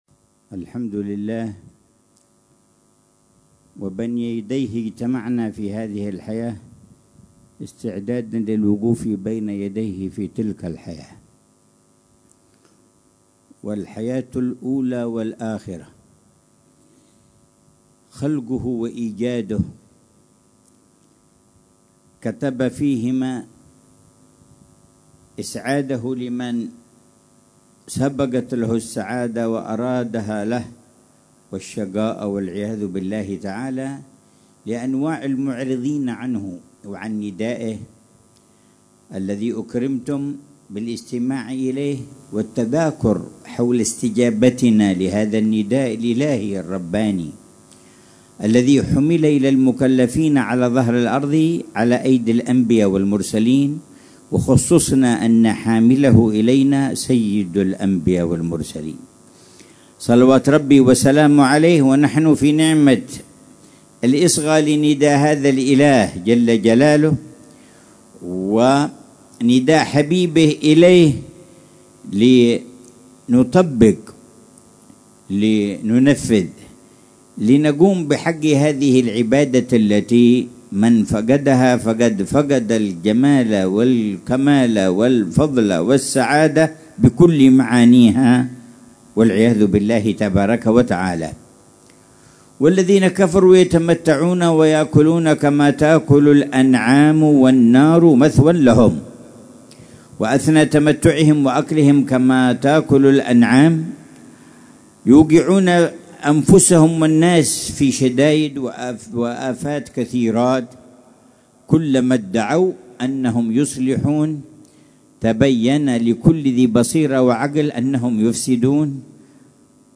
محاضرة العلامة الحبيب عمر بن محمد بن حفيظ في جلسة الجمعة الشهرية الـ59، شرقي جامع التوفيق، باستضافة حارتي التوفيق والإيمان بمدينة تريم، ليلة السبت 19 ذو القعدة 1446هـ، بعنوان: